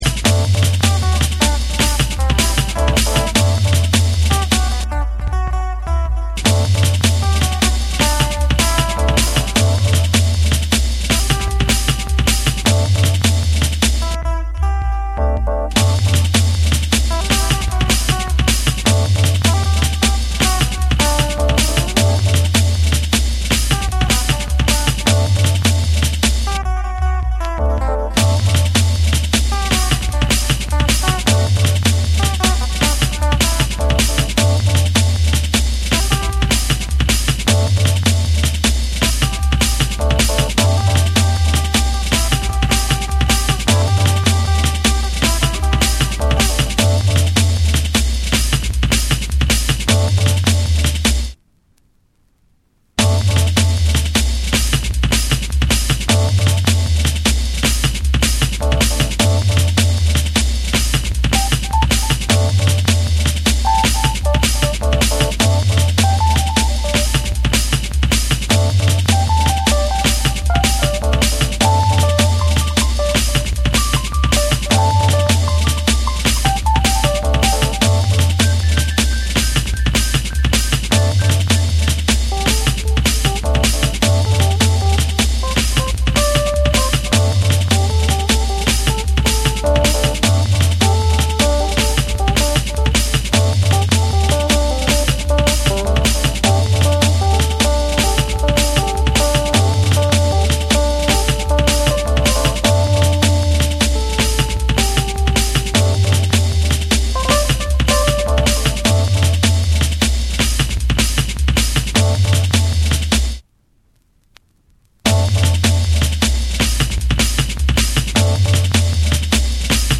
重厚なブレイクビーツ、深みのあるベースライン、浮遊感のあるシンセのメロディーが織りなすドラムンベース金字塔的アルバム！
JUNGLE & DRUM'N BASS